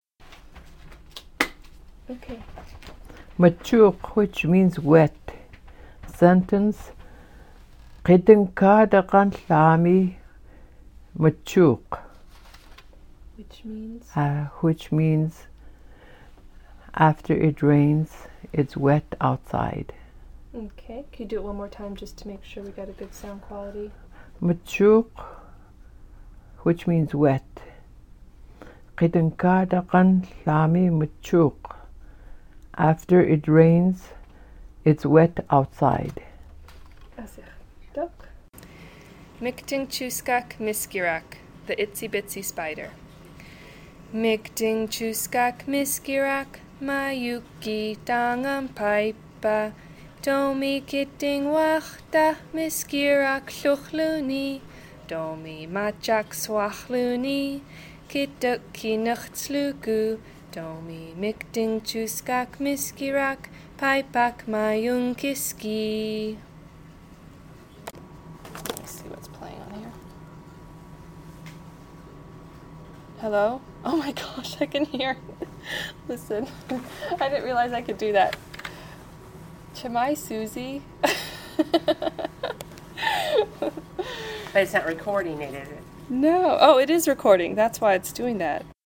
Kodiak, Alaska
Alutiiq Word of the Week, Alutiiq songs